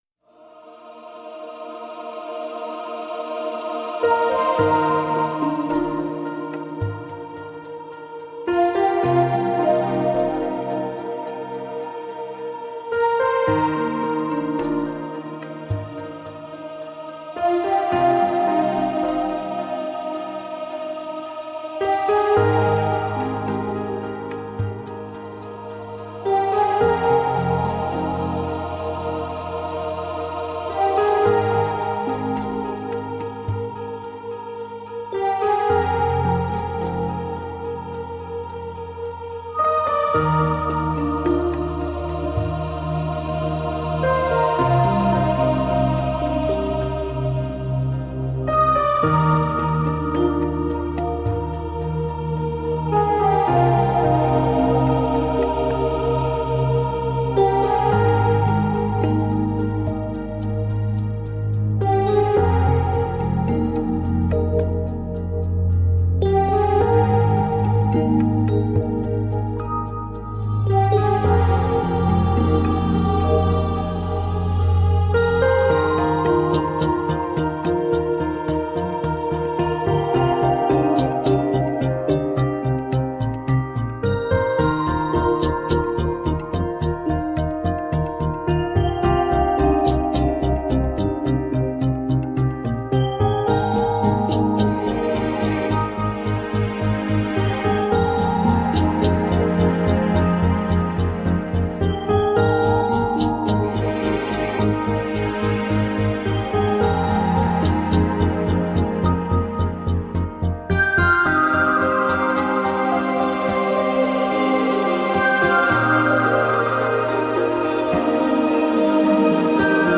Tagged as: New Age, World, Chillout, Indian Influenced